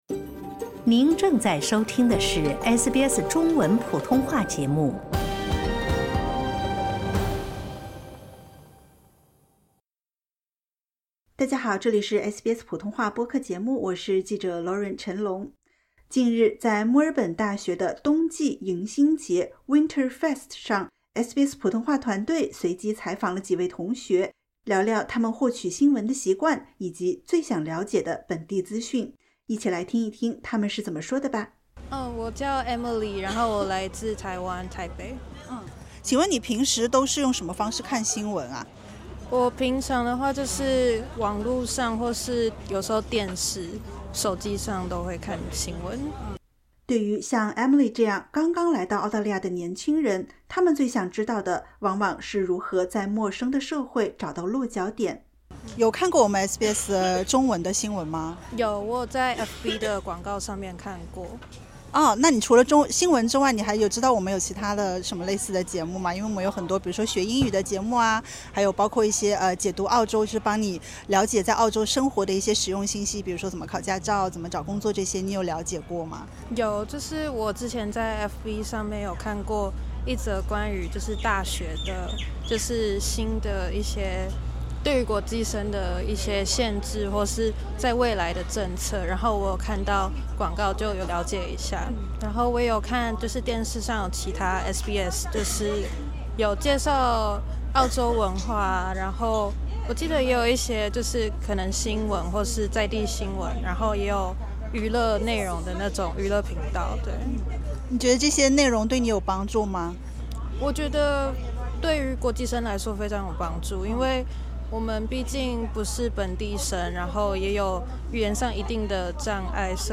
在墨尔本大学一年一度的冬季迎新节（WinterFest）上，SBS中文团队与一群年轻的华人留学生聊了聊他们的媒体习惯、文化身份与对本地生活的观察。